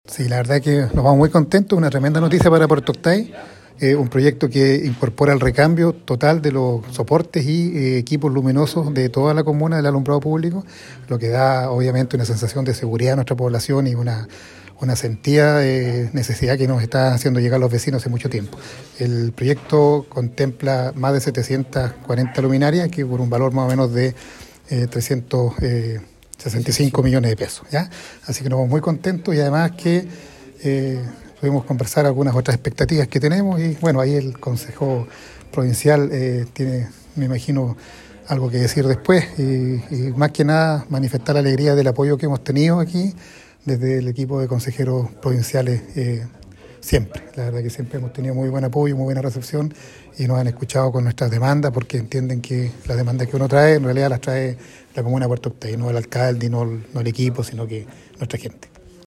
Este proyecto incorpora el recambio total de los soportes y los equipos luminosos de la comuna de Puerto Octay, que equivale a una inversión de 365 millones de pesos, lo que era una necesidad de los vecinos y vecinas de la comuna lacustre como lo señaló el Alcalde Gerardo Gunckel.